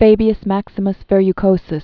(fābē-əs măksə-məs vĕr-y-kōsəs, --), Quintus Known as "the Cunctator."